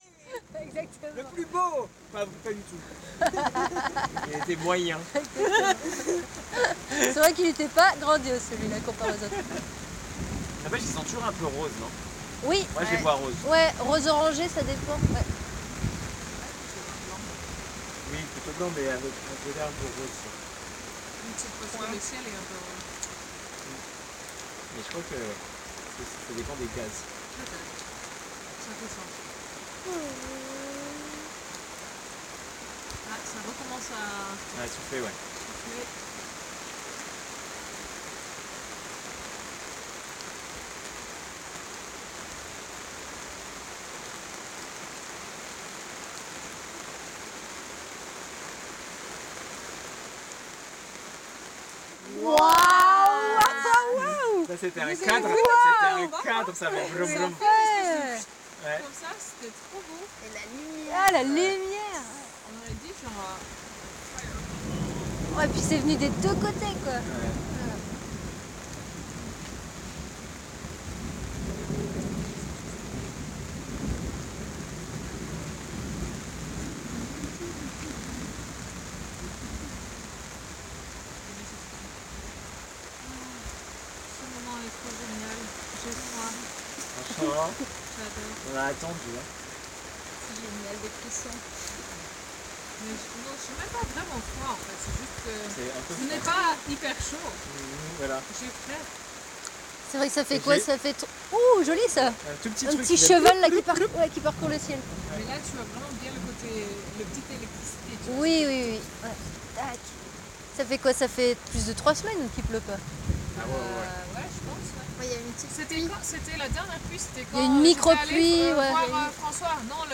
Célébrer la pluie qui tombe, qui nous rafraîchit, qui régénère le sol. La puissance des éclairs qui déchirent le ciel. Être en joie ensemble à partager cette énergie. Cette pulsation électrique intermittente fugace, témoin des forces naturelles du Vivant. Et rire, rire d’émerveillement !